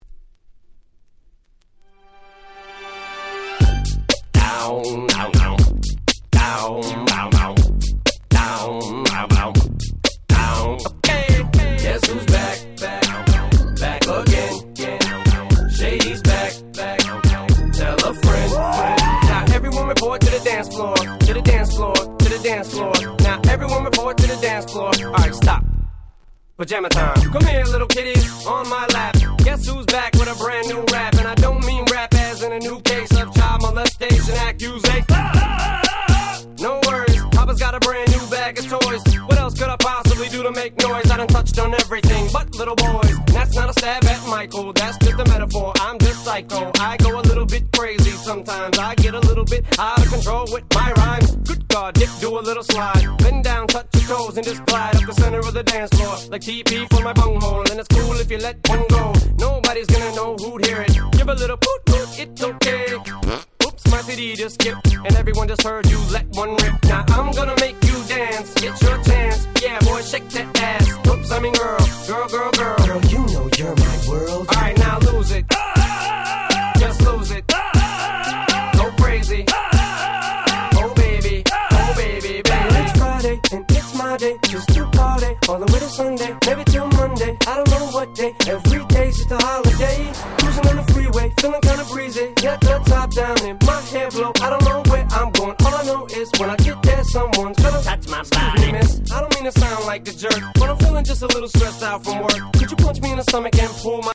04' Super Hit Hip Hop !!
タイプのアップナンバー！！